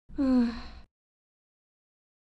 Woman Sigh 2
Woman Sigh 2 is a free sfx sound effect available for download in MP3 format.
yt_4yfJ04HDqxs_woman_sigh_2.mp3